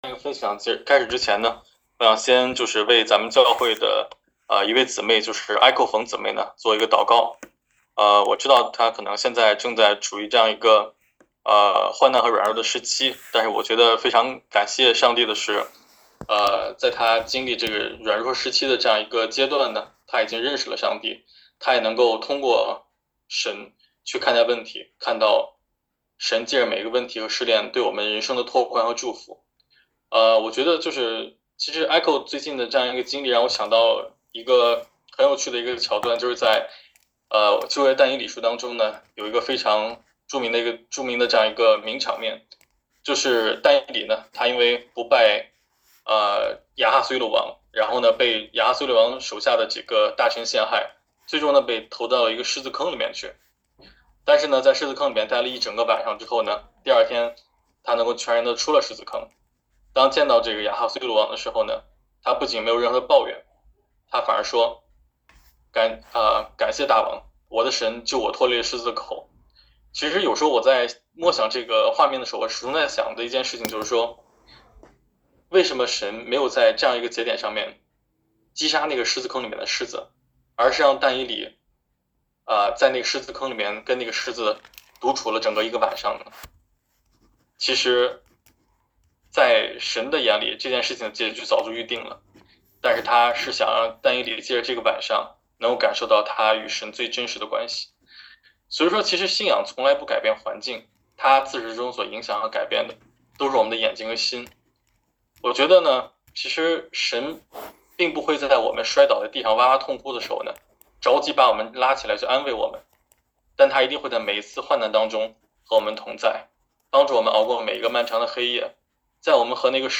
《到底是以牙还牙还是…》主日证道.mp3